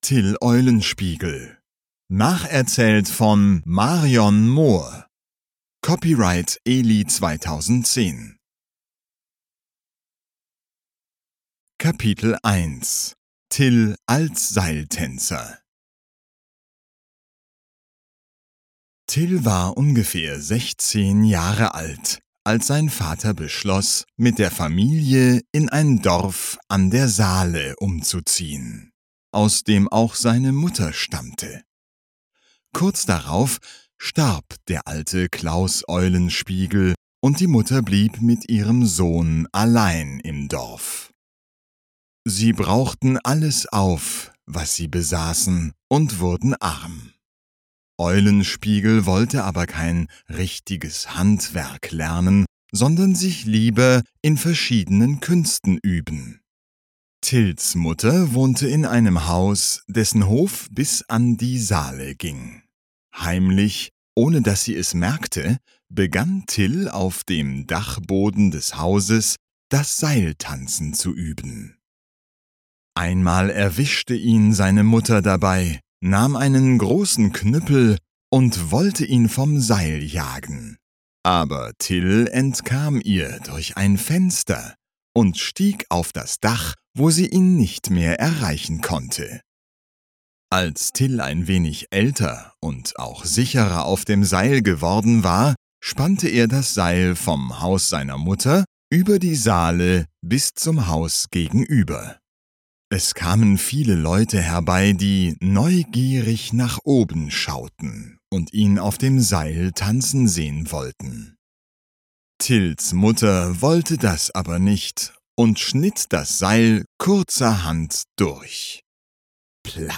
Obtížnost poslechu odpovídá jazykové úrovni A2 podle Společného evropského referenčního rámce, tj. pro studenty němčiny na úrovni mírně pokročilých začátečníků.
AudioKniha ke stažení, 7 x mp3, délka 39 min., velikost 48,3 MB, německy